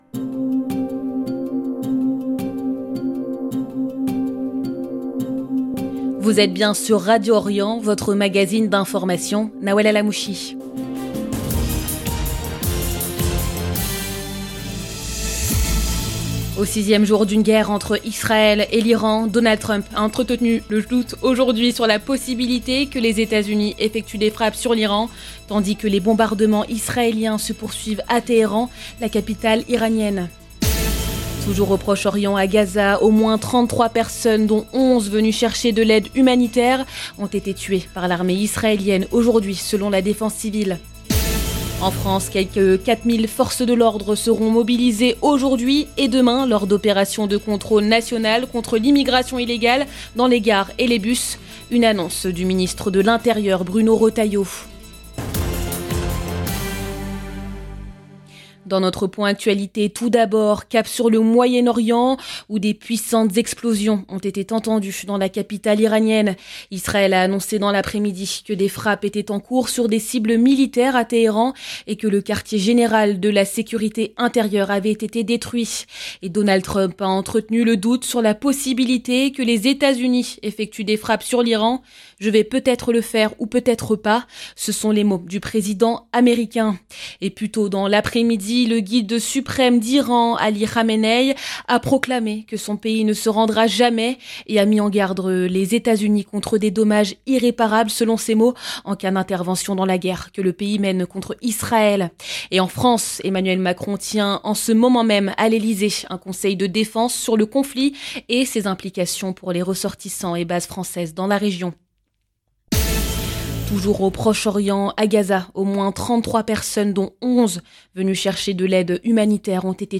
Magazine de l'information de 17H00 du 18 juin 2025